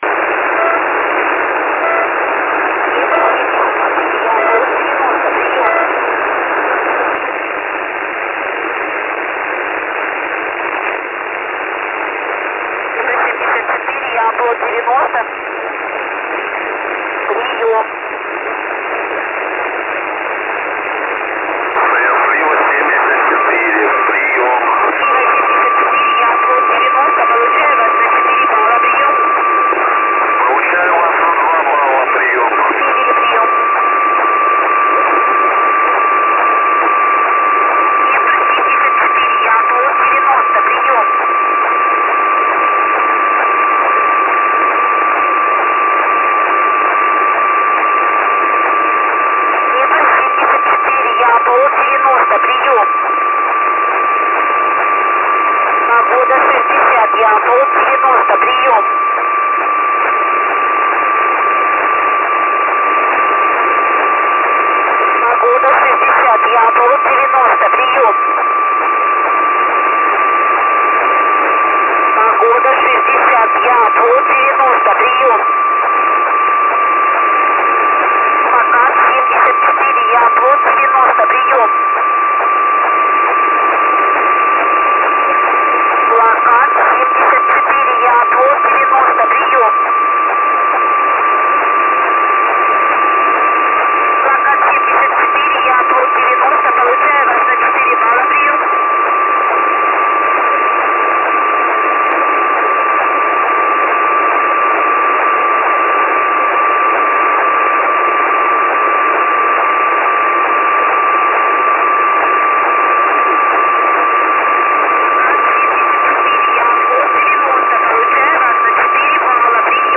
Проверка связи на 7056 и 6451 кГц